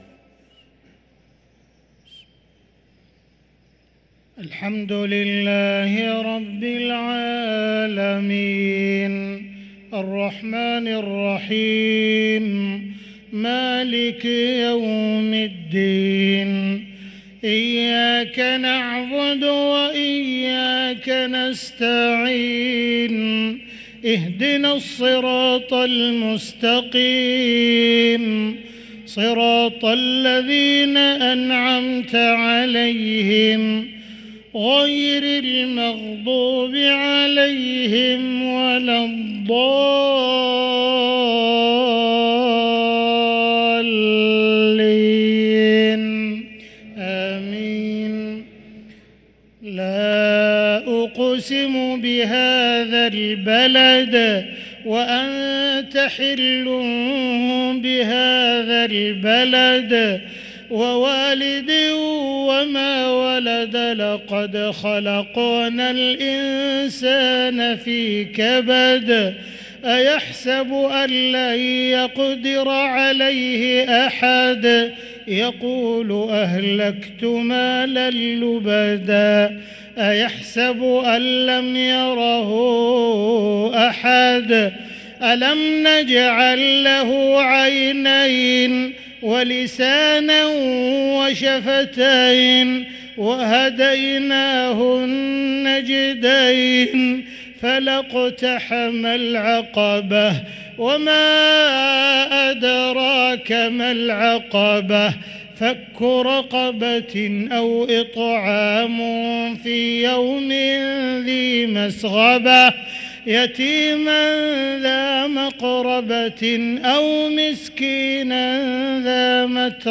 صلاة المغرب للقارئ عبدالرحمن السديس 20 رمضان 1444 هـ
تِلَاوَات الْحَرَمَيْن .